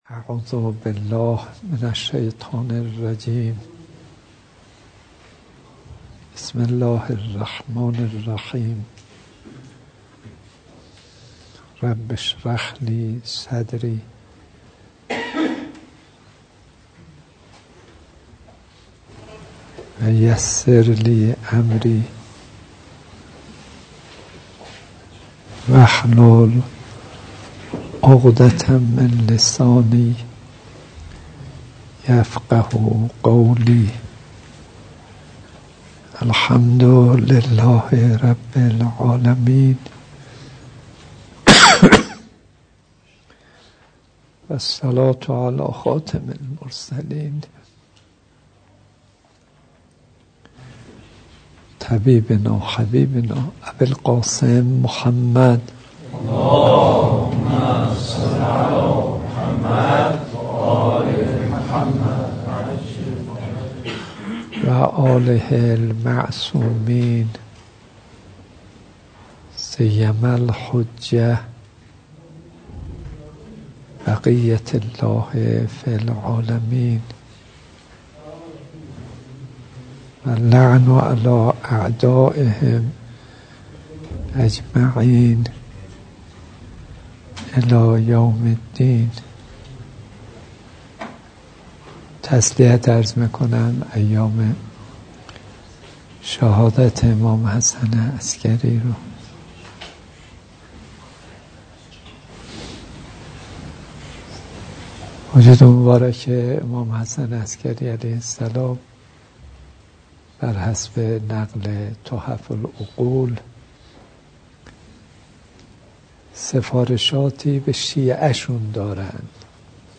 به گزارش خبرنگار فرهنگی باشگاه خبرنگاران پویا، حجت‌الاسلام والمسلمین کاظم صدیقی؛ امام جمعۀ موقت تهران شنبه 4 آذرماه در درس اخلاق خود که در مسجد حوزه علمیه امام خمینی(ره) تهران با حضور معاونت‌ها، اساتید و طلاب برگزار شد، طی سخنانی با اشاره به سفارشاتی از امام حسن عسگری(ع) در کتاب تحف العقول خطاب به شعیان گفت: صدق گفتار، ادای امانت و اجتهاد در طاعت برنامه‌های عملی امام عسگری(ع) محسوب می‌شود.